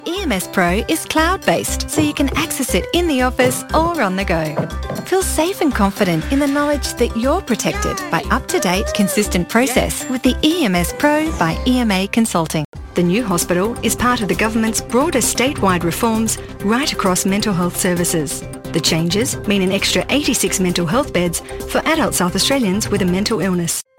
Female
English (Australian)
Yng Adult (18-29), Adult (30-50)
I'm described as natural and friendly and booked by studios looking for young & perky, a mum, energetic, smooth or seductive.
Explainer Videos
0724Corporate_video.mp3